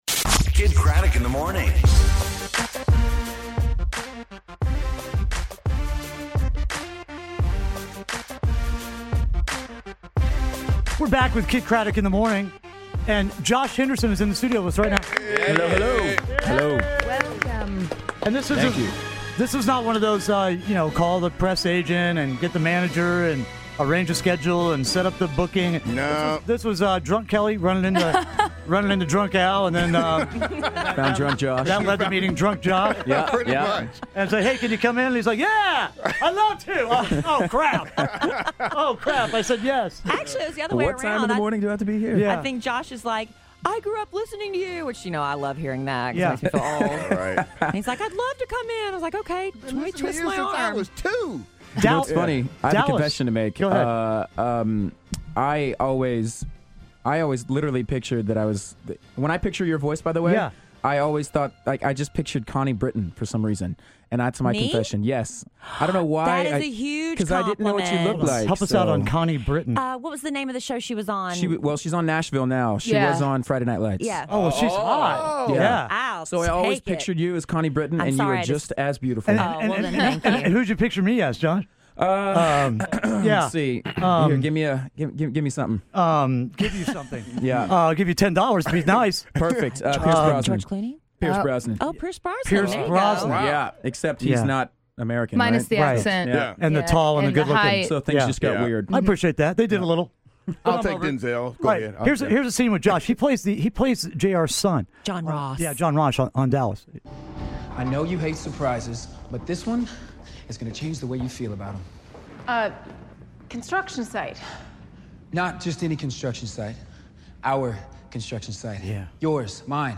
Josh Henderson Interview
Kidd Kraddick in the Morning interviews Josh Henderson from Dallas.